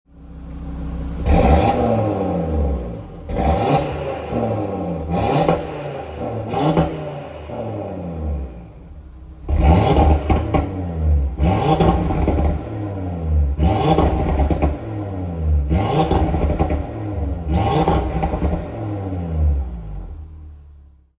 • Aluminium Engine Block Model (Non-OPF DAZA Engine that is louder than post 2019 models)
Listen to the 5-cylinder fury
• RS Sports Exhaust System with Black Oval Tips (£1,000)
UHP-revs.mp3